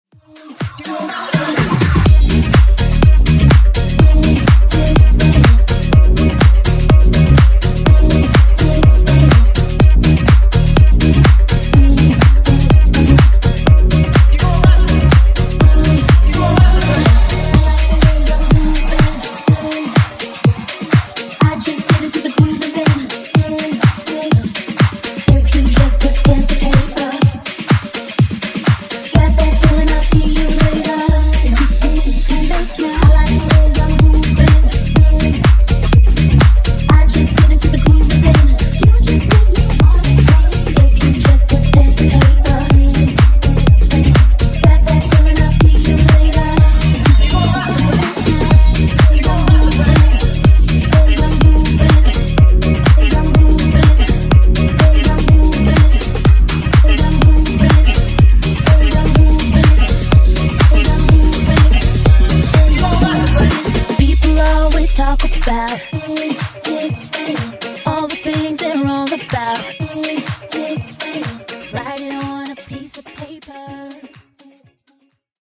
Genres: R & B , RE-DRUM , TOP40
Clean BPM: 100 Time